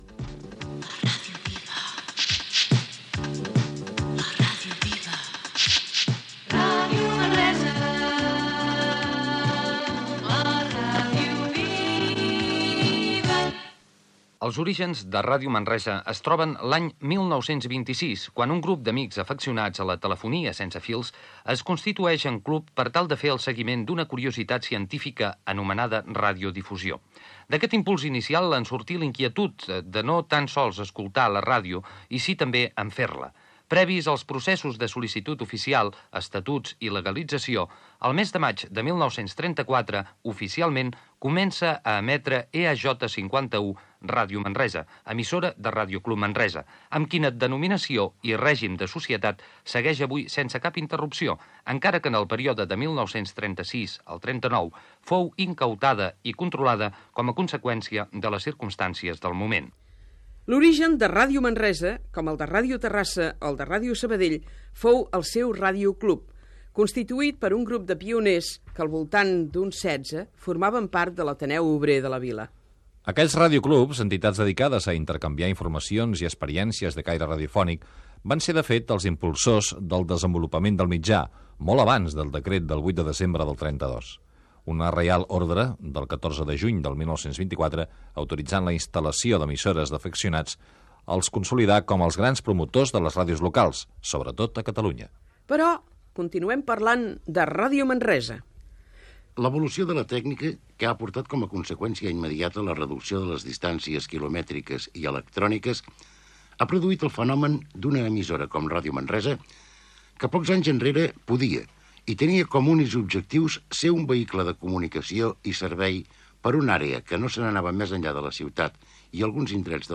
Indicatiu de Ràdio Manresa.
Divulgació